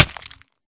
damage / hurtflesh3